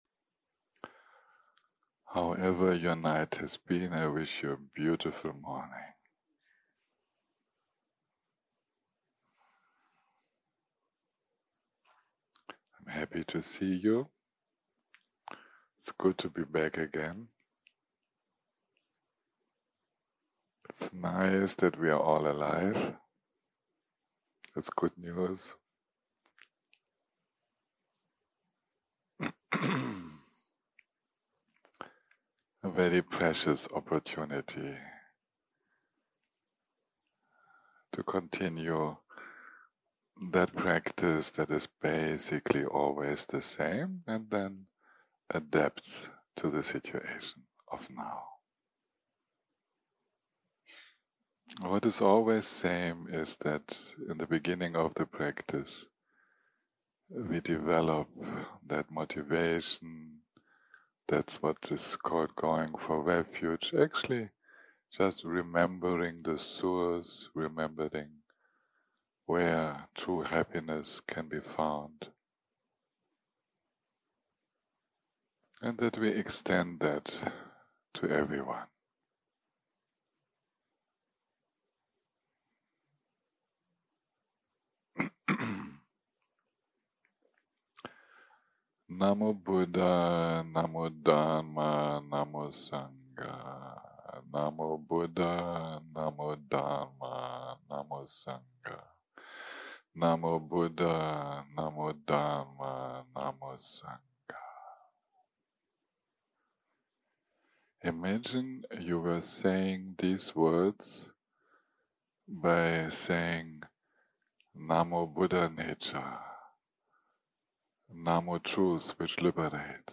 יום 7 - הקלטה 30 - בוקר - מדיטציה מונחית - Tong Len 1 - Sharing and Receiving 0:00 0:00 סוג ההקלטה: סוג ההקלטה: מדיטציה מונחית שפת ההקלטה: שפת ההקלטה: אנגלית